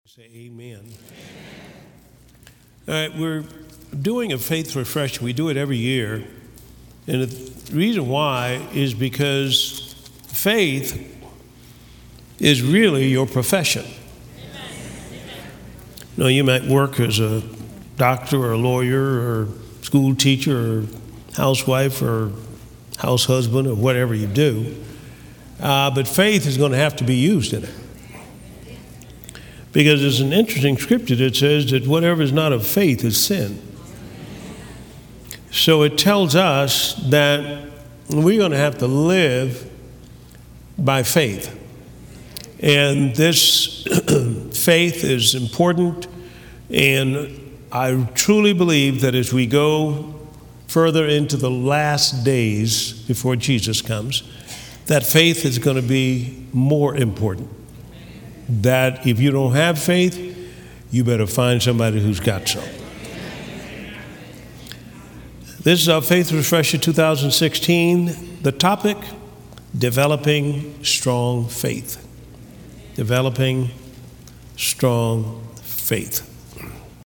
(3 Teachings) Are you ready to take your faith to the next level?